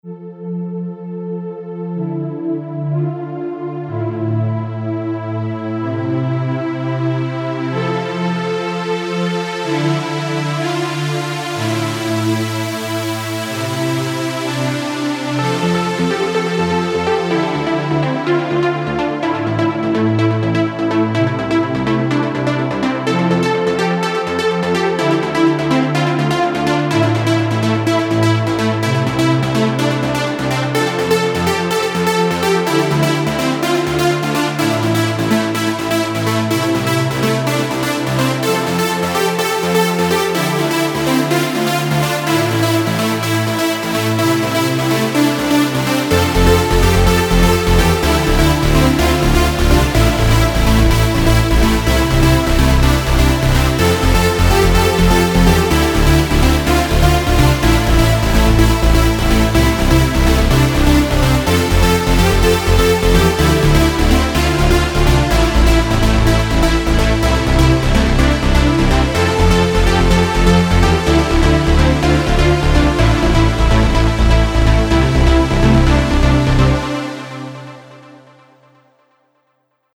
Dune 2 на лиде и пэде, бас - вирус.